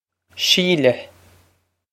Síle Shee-leh
This is an approximate phonetic pronunciation of the phrase.